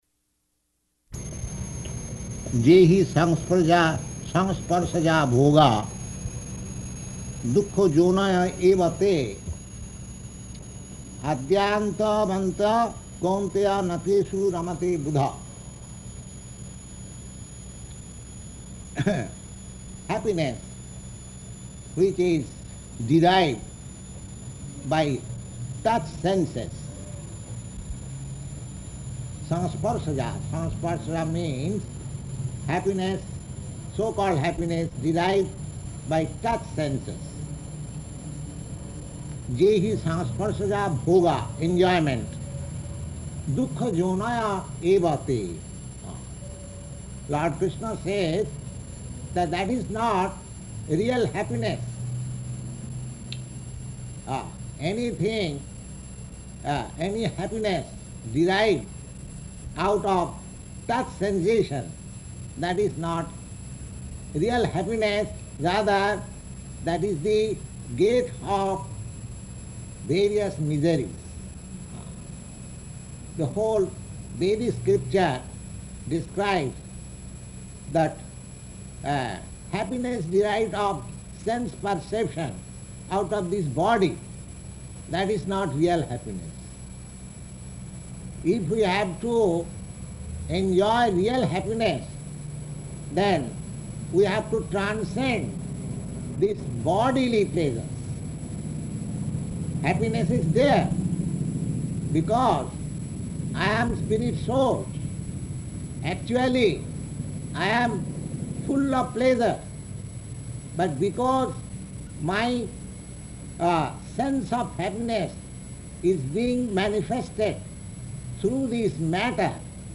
Location: New York